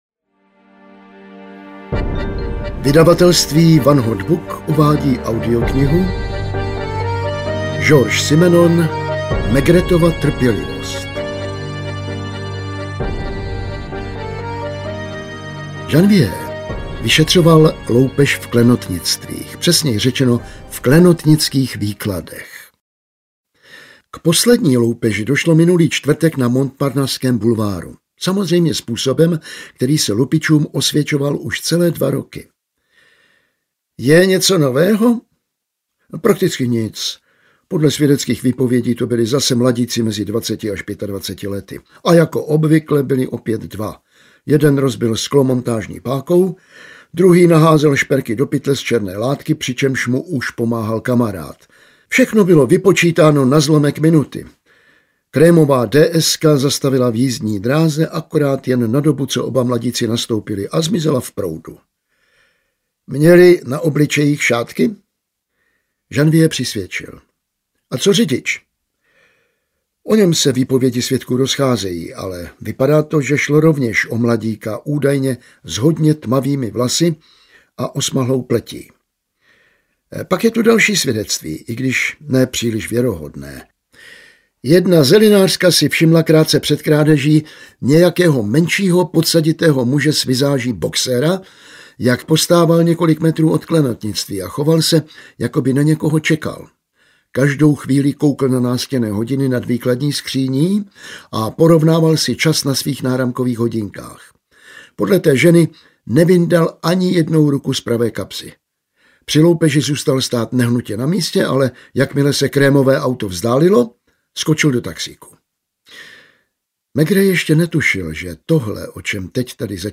Maigretova trpělivost audiokniha
Ukázka z knihy
• InterpretJan Vlasák